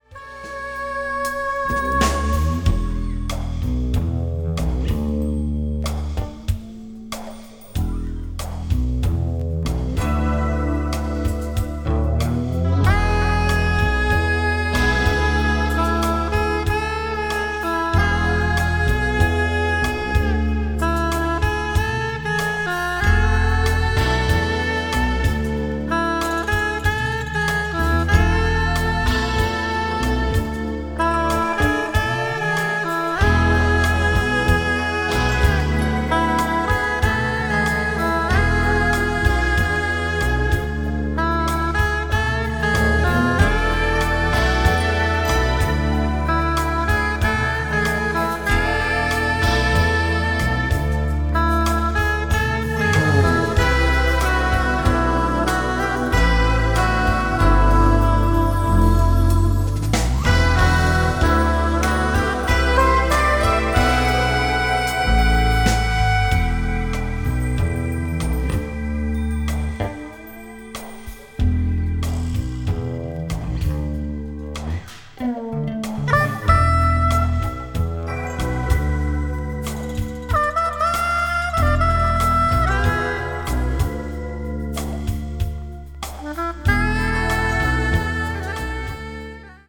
media : EX/EX(some slightly noises.)
crossover   fusion   jazz groove